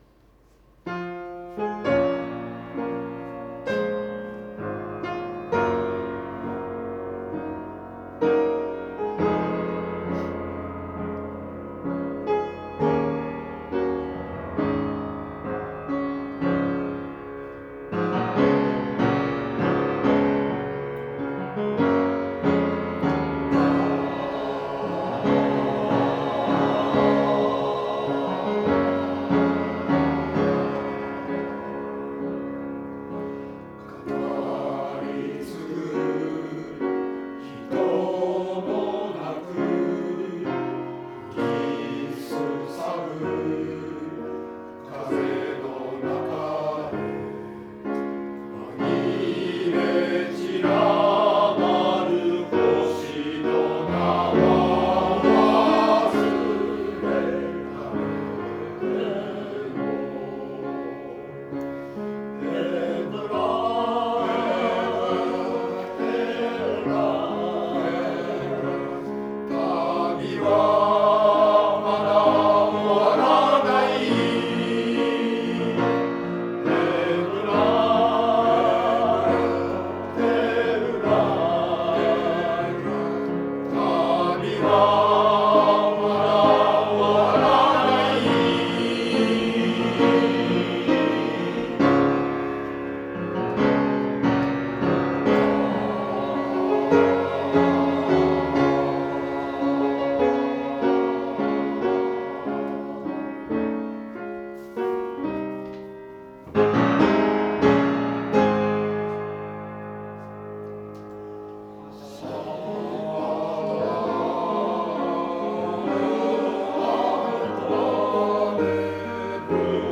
合唱祭が近づく、湖北台市民センターでの練習